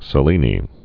(sə-lēnē)